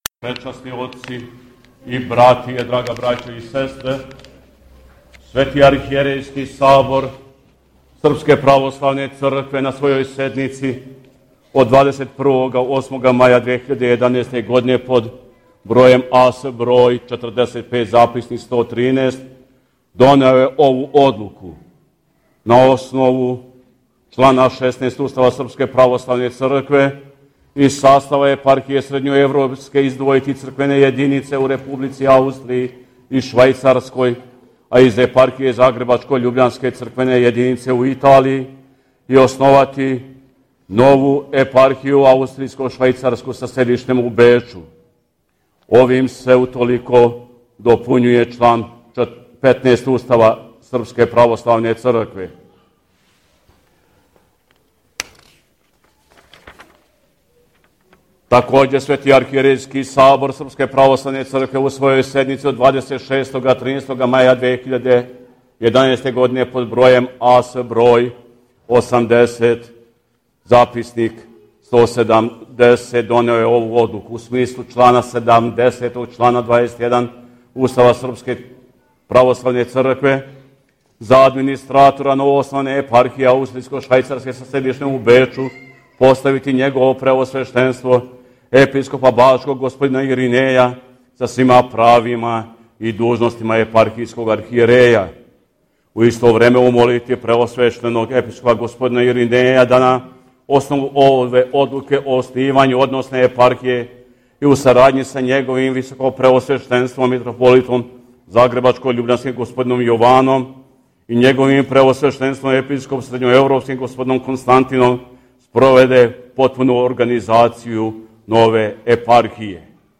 После свете Литургије, Епископ Константин је прочитао одлуку Светог Архијерејског Сабора Српске Православне Цркве о оснивању Епархије аустријско-швајцарске са седиштем у Бечу и одлуку о постављењу Епископа бачког Господина др Иринеја за администратора ове новоформиране епархије.